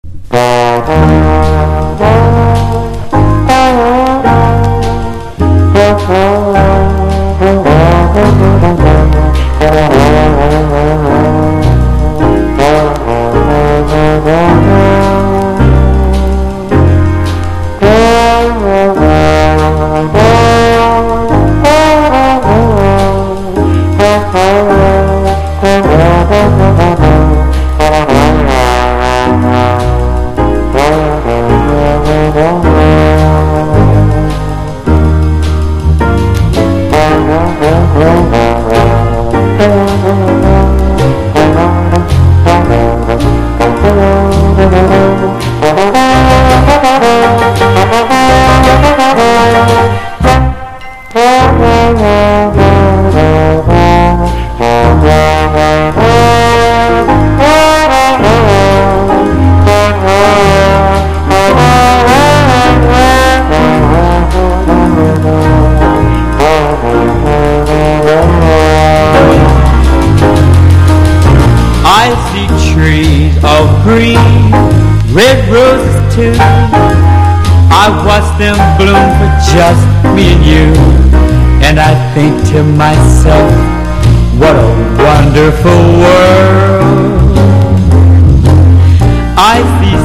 MODERN JAZZ